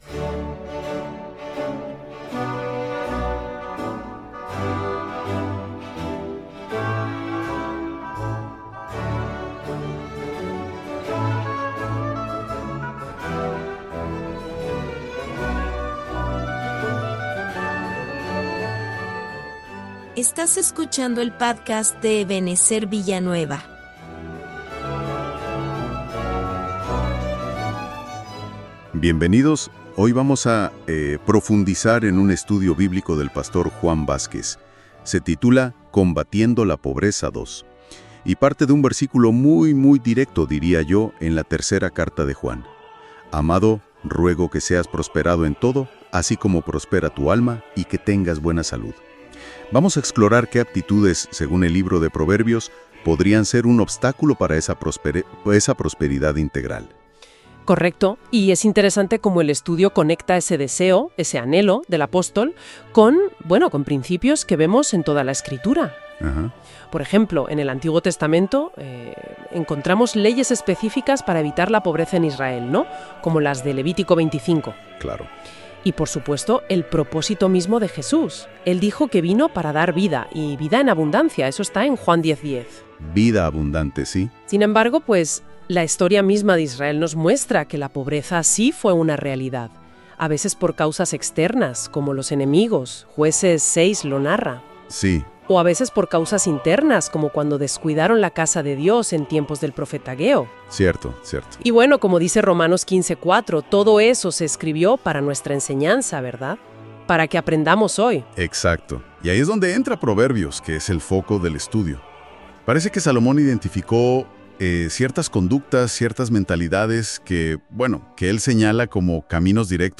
Estudio bíblico sobre cómo combatir la pobreza según Proverbios. Descubre 5 causas de pobreza espiritual y material, y cómo aplicar principios bíblicos para prosperar según 3 Juan 1:2 y Juan 10:10.